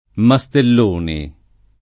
[ ma S tell 1 ni ]